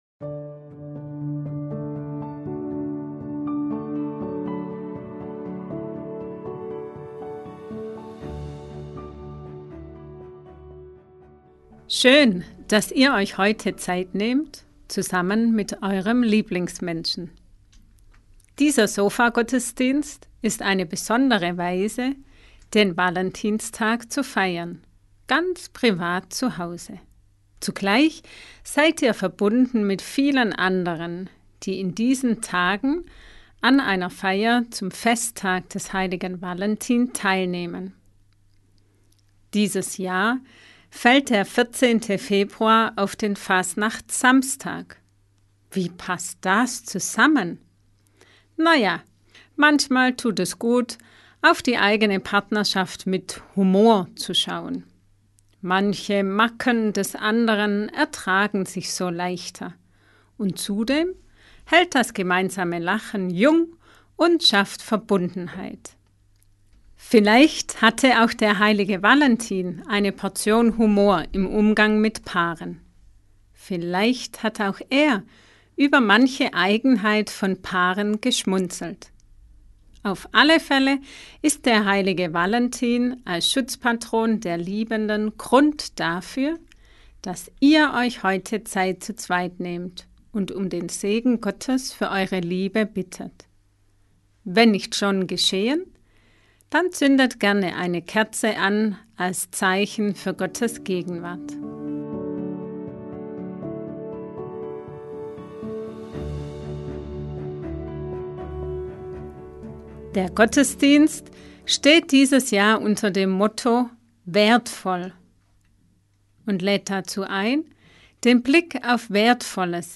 audio_sofagottesdienst_mit_musik.mp3